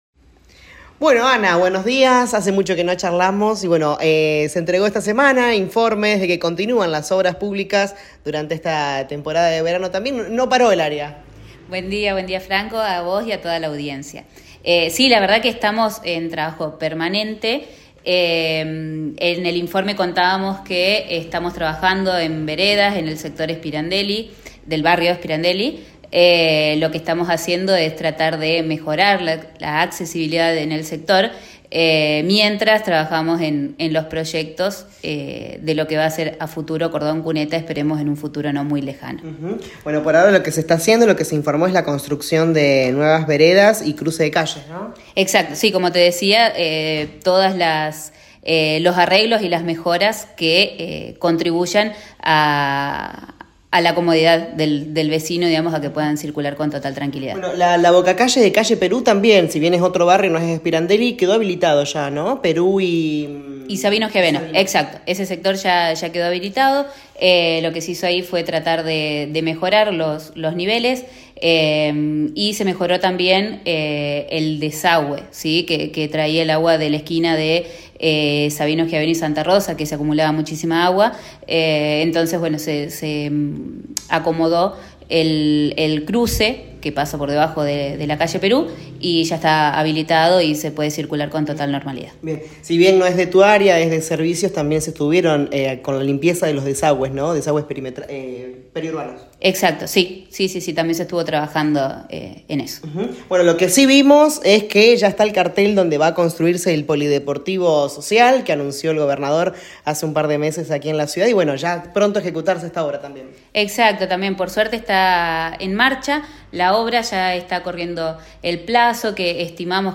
La secretaria de obras Arq. Analía Ledesma dialogó con LA RADIO 102.9 y comentó las obras en las que está trabajando el Municipio y proyectos para llevar adelante en el 2025.